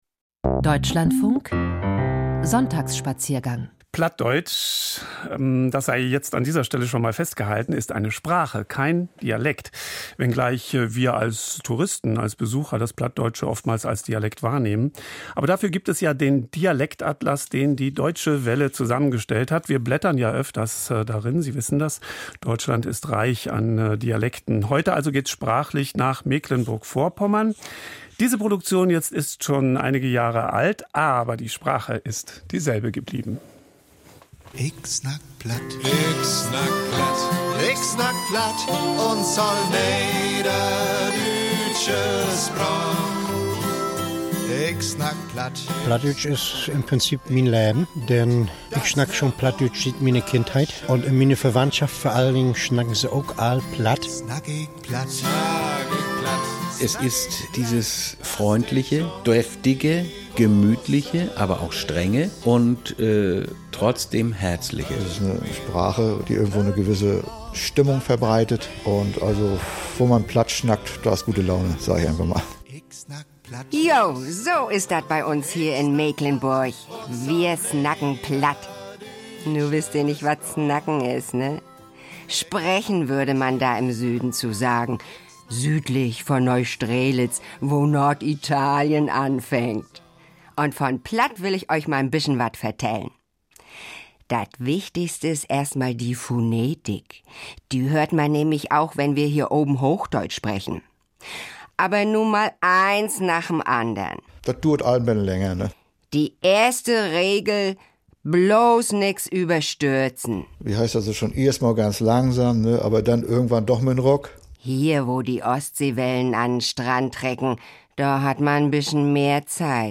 Dialektatlas: Mecklenburger Plattdeutsch